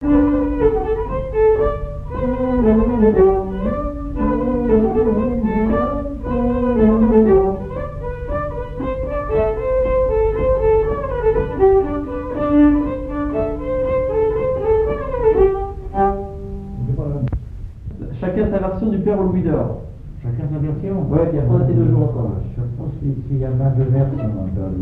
Chants brefs - A danser
polka piquée
Airs à danser aux violons et deux chansons
Pièce musicale inédite